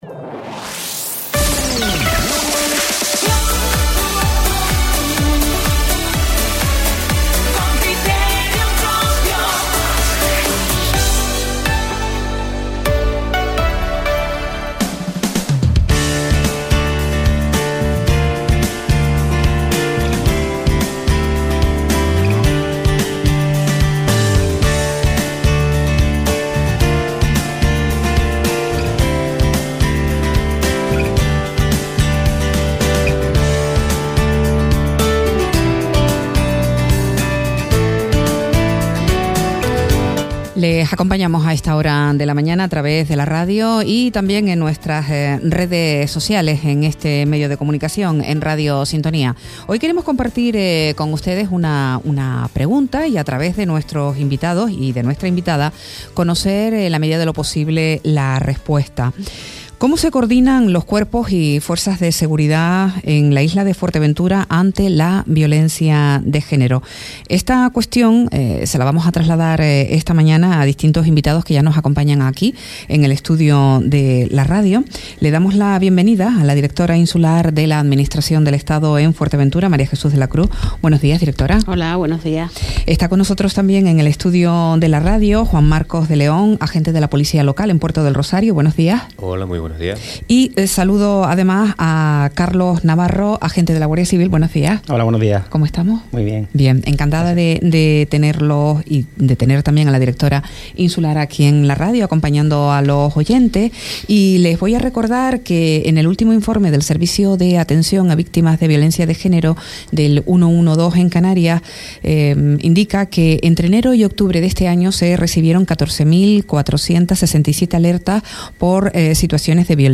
Entrevistas ¿Cómo se coordinan los Cuerpos y Fuerzas de Seguridad ante la violencia de género en Fuerteventura?